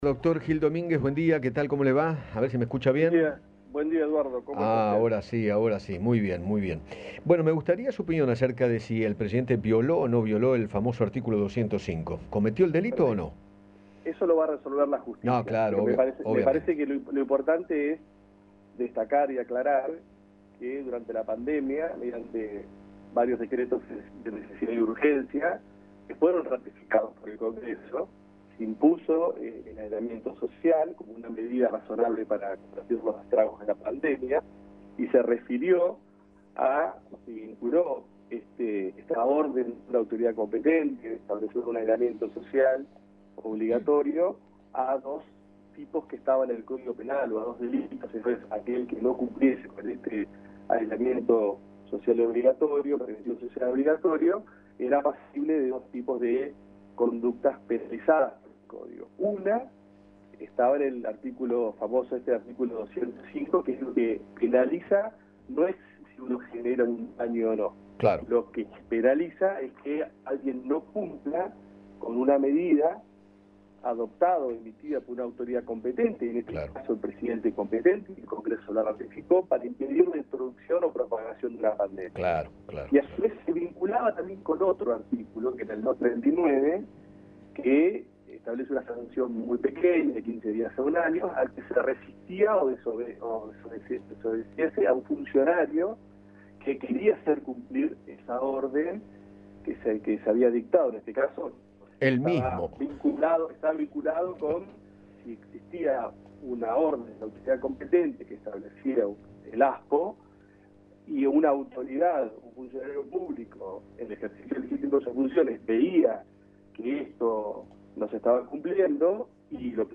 Además, habló con el ex fiscal Manuel Garrido.